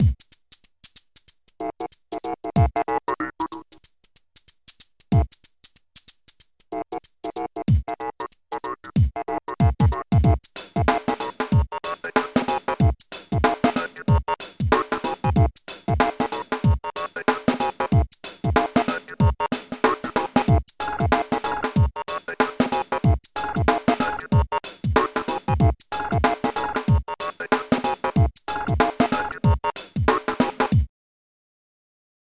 Tout ça vient de fast tracker II, sous Dos, à l'époque ou bill gates n'était pas encore maître du monde, à savoir avant 1997.
Dans le meme style ça pourrait faire une bonne boucle bien ridicule, c'est